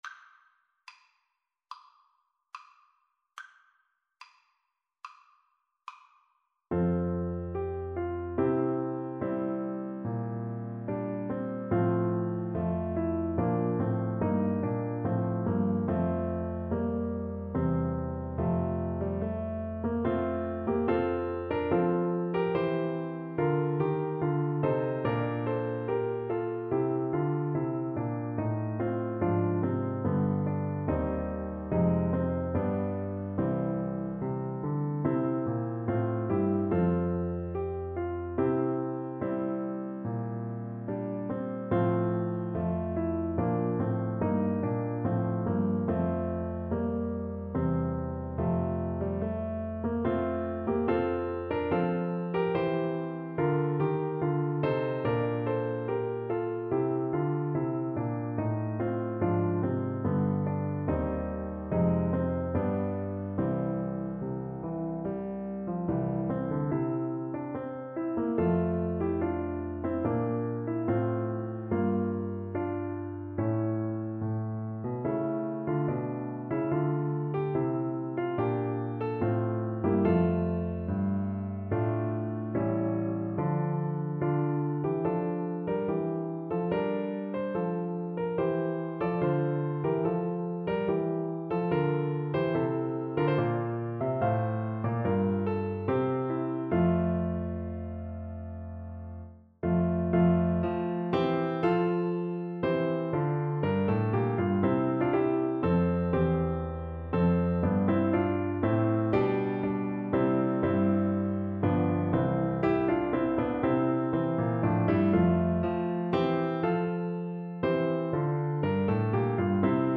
Flute version
= 72 Rather slow
4/4 (View more 4/4 Music)
Flute  (View more Intermediate Flute Music)
Classical (View more Classical Flute Music)